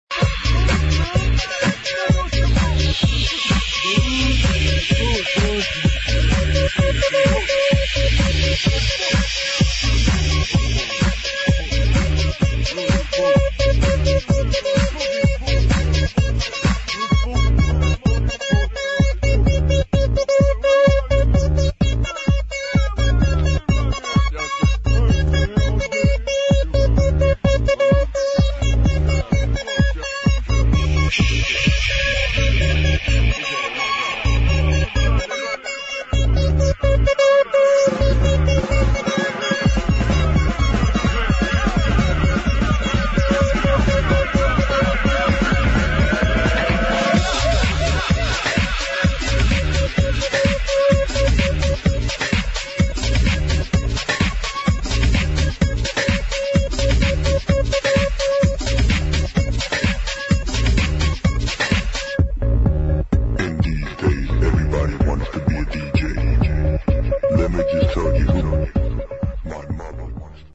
[ FUNKY HOUSE ]